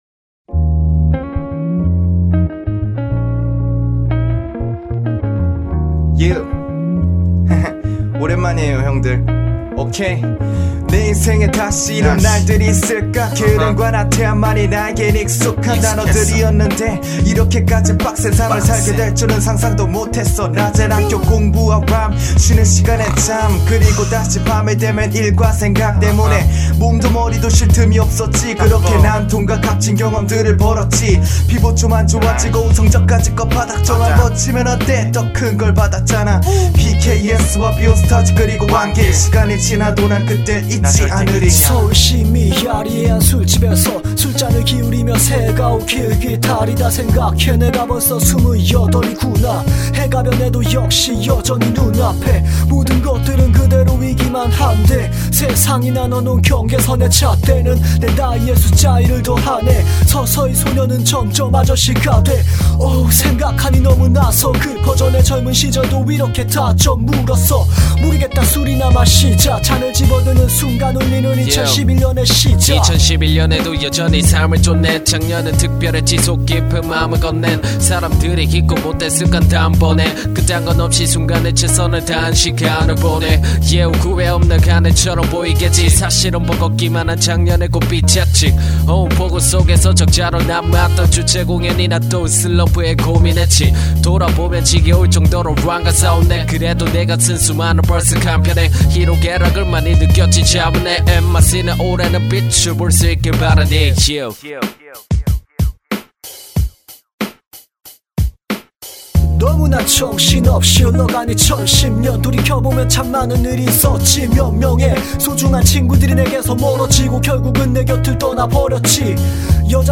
REMIX.
올해 초에 UFO 마이크를 구입해서 녹음한건데 괜찮은것 같아서 한번 올려봅니다!!